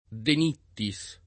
De Nittis [ de n & tti S ] cogn.